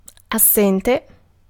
Ääntäminen
US : IPA : [ˈæb.sənt]